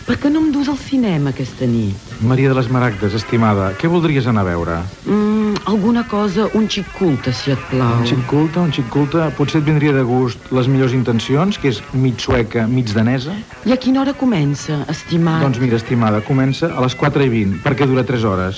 Diàleg